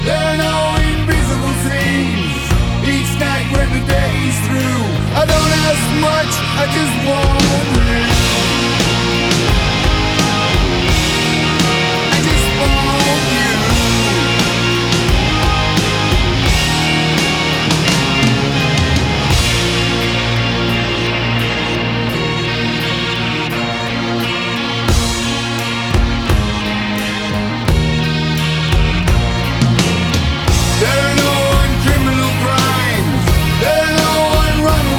Hard Rock Rock Metal Arena Rock
Жанр: Рок / Метал